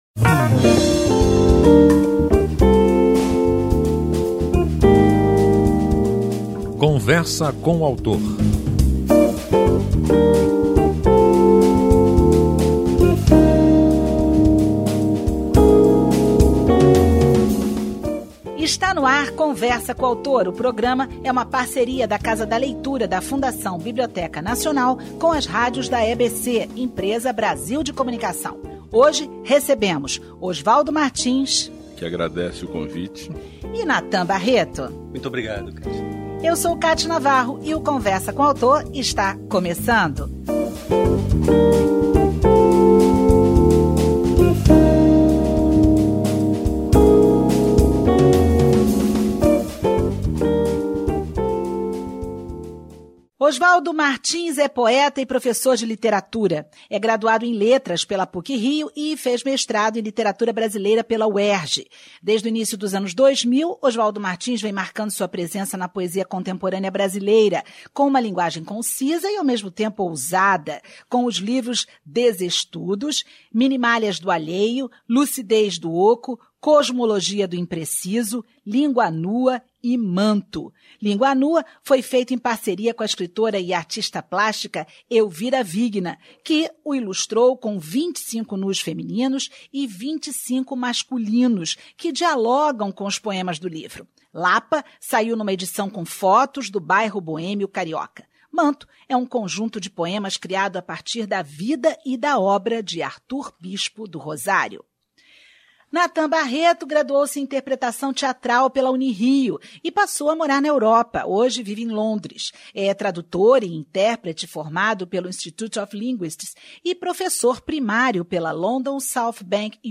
Gravação do programa Conversa com o Autor